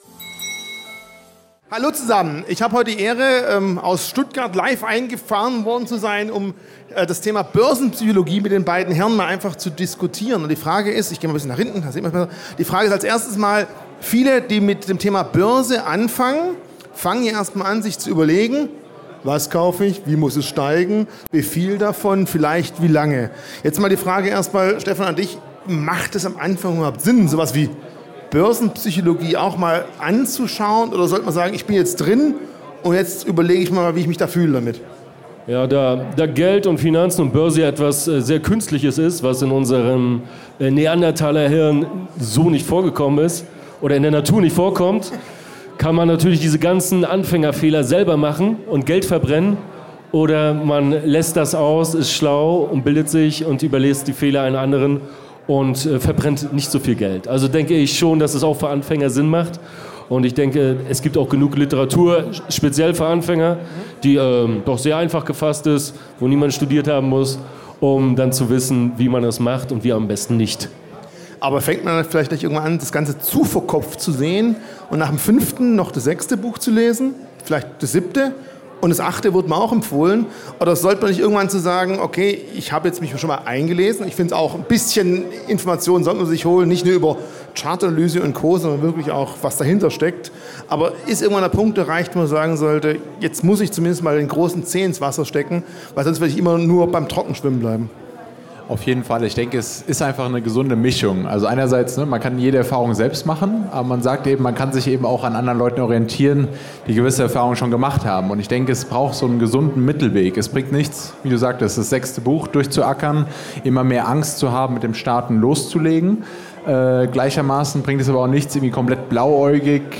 Beschreibung vor 1 Jahr Ein besonderes Highlight auf dem diesjährigen Börsentag Zürich war die BX Swiss Blogger-Lounge.